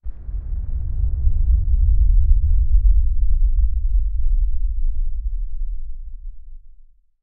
На этой странице представлены записи звуков атомного взрыва — мощные, устрашающие и заставляющие задуматься о хрупкости мира.
Космический гул атомного распада